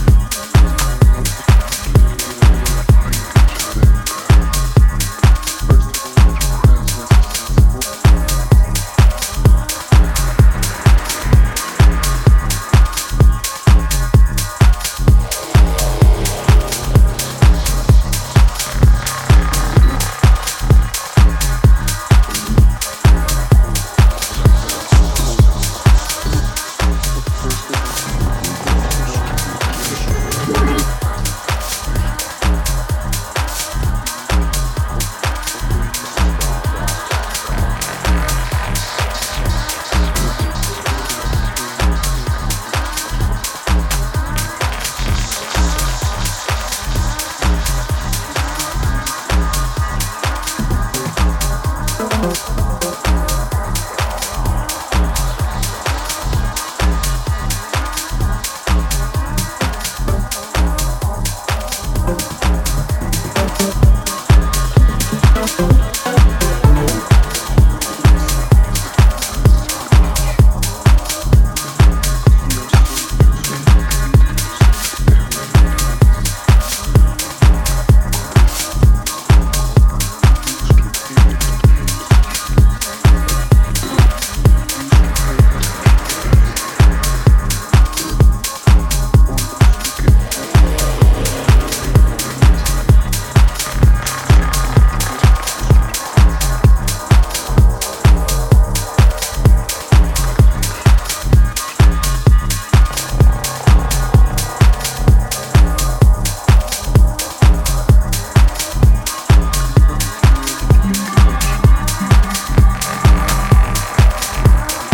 blend House grooves with minimalistic elements